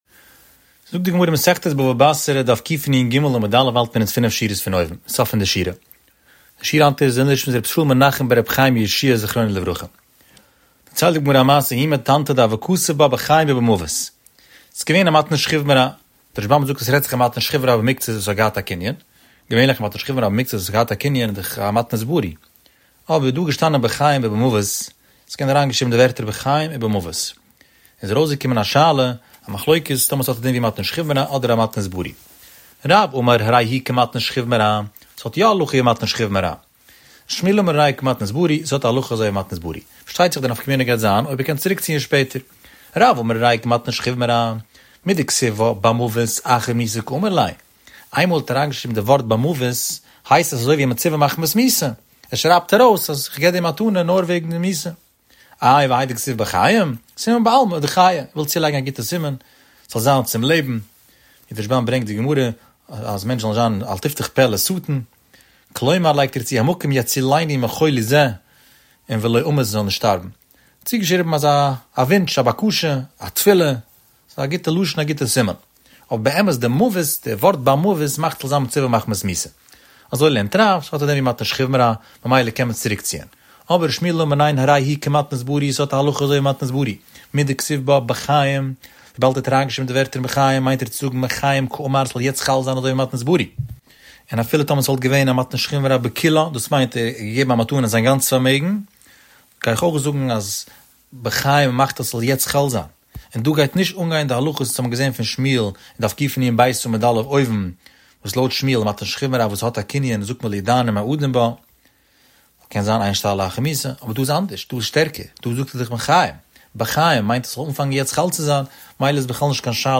The daily daf shiur has over 15,000 daily listeners.